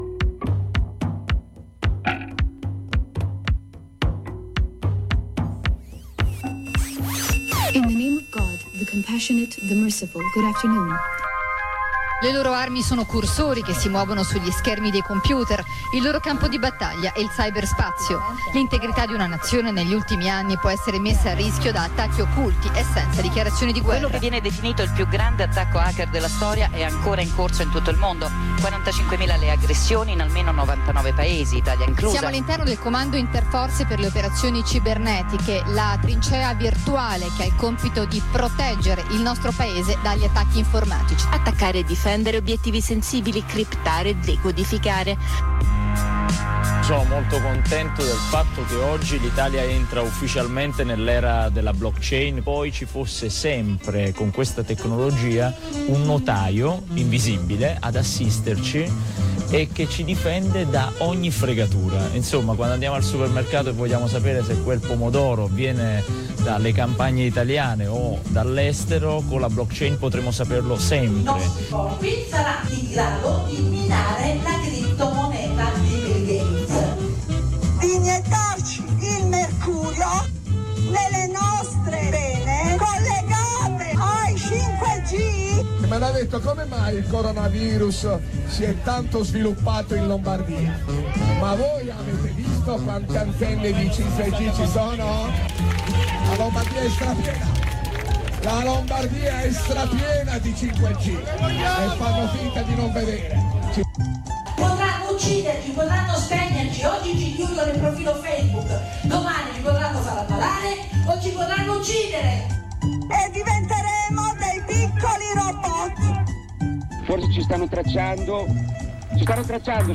Solite chiacchere confuse, ma con una bella intervista su un attacco particolare che sta avvenendo in questi giorni ai danni dei server tor.